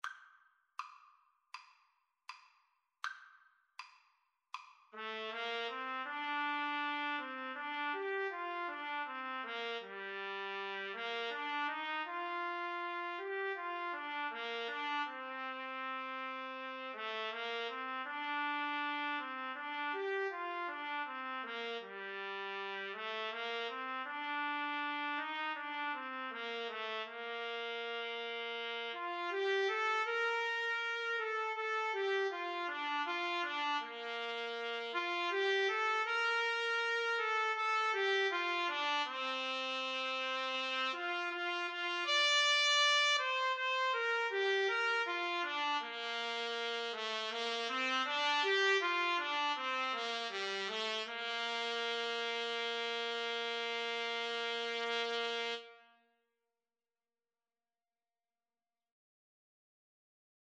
4/4 (View more 4/4 Music)
Bb major (Sounding Pitch) C major (Trumpet in Bb) (View more Bb major Music for Trumpet-Trombone Duet )
Espressivo Andante
Trumpet-Trombone Duet  (View more Easy Trumpet-Trombone Duet Music)
Traditional (View more Traditional Trumpet-Trombone Duet Music)